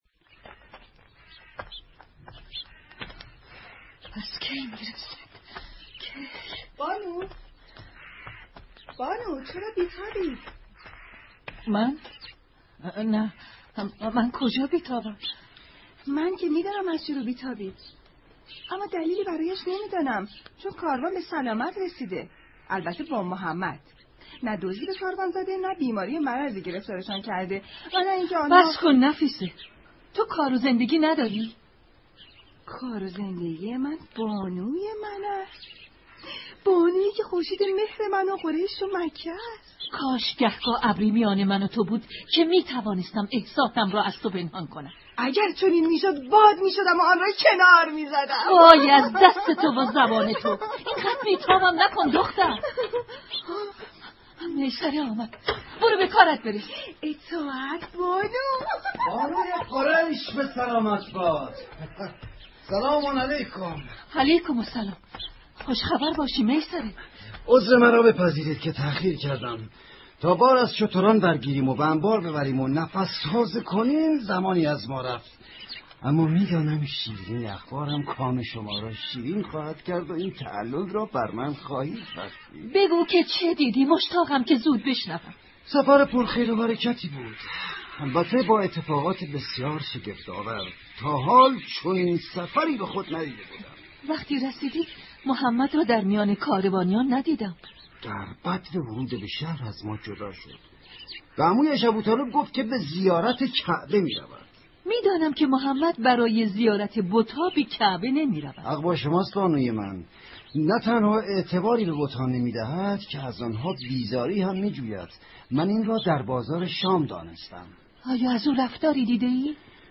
نمایشنامه صوتی - خورشید مهر - متفرقه با ترافیک رایگان